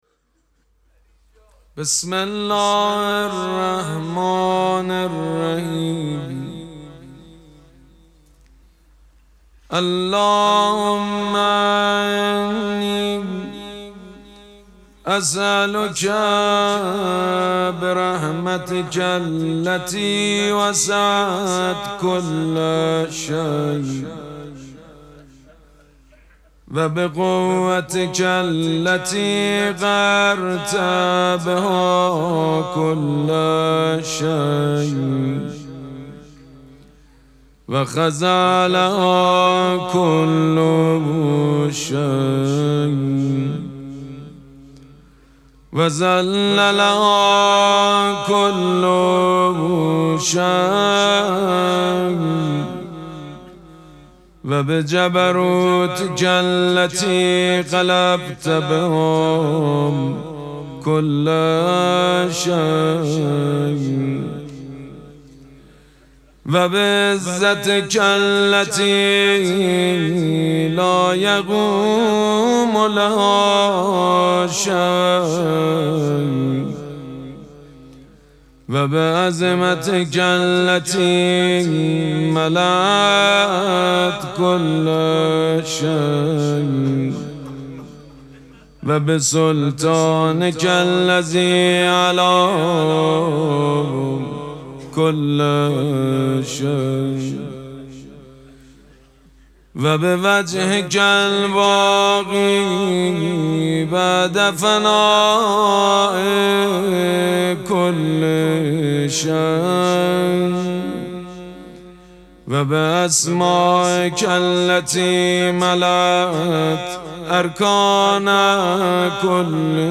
مراسم مناجات شب ششم ماه مبارک رمضان پنجشنبه ۱۶ اسفند ماه ۱۴۰۳ | ۵ رمضان ۱۴۴۶ حسینیه ریحانه الحسین سلام الله علیها
مداح حاج سید مجید بنی فاطمه